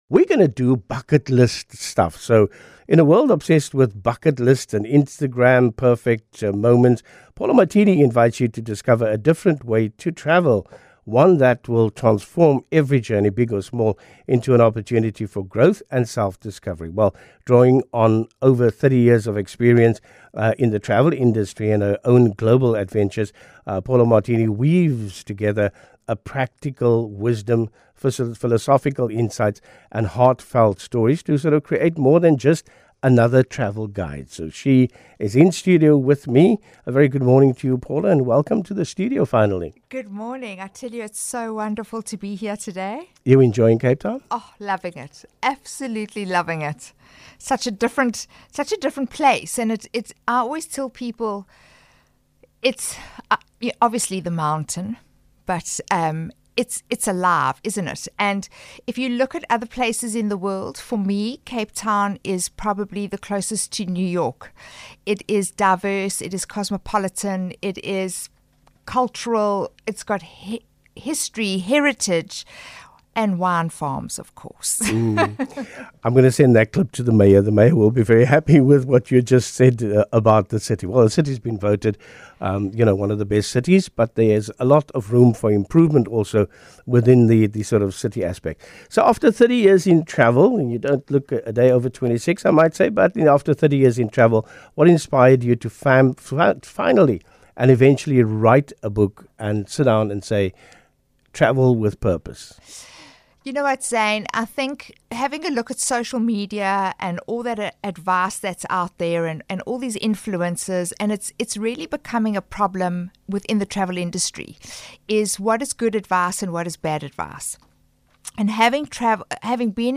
As shared on CapeTalk, 702, and 100.5FM Recent Radio Feature